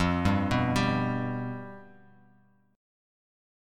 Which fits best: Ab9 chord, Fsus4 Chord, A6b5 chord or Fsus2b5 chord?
Fsus2b5 chord